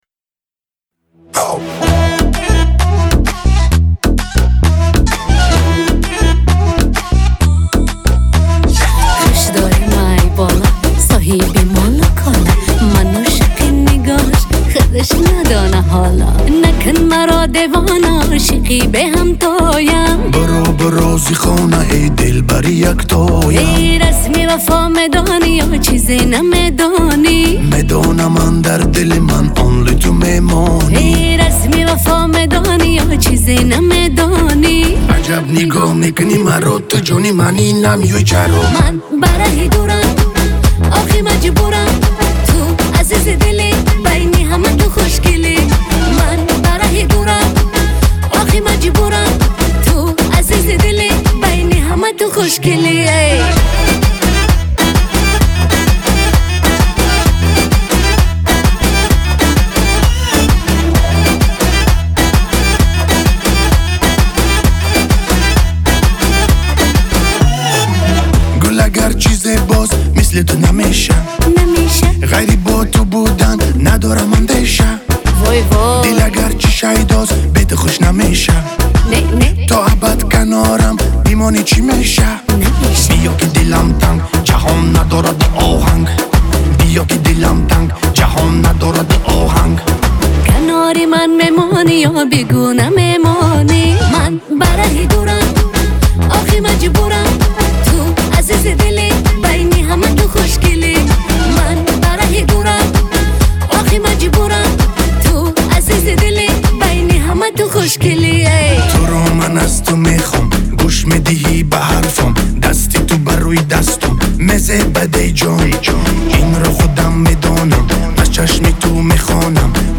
Музыка / 2026-год / Таджикские / Клубная / Поп